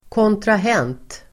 Ladda ner uttalet
kontrahent substantiv, contracting party Uttal: [kåntrah'en:t] Böjningar: kontrahenten, kontrahenter Definition: part (i avtal o dyl) contracting party substantiv, kontrahent Förklaring: part (i avtal och dylikt)
kontrahent.mp3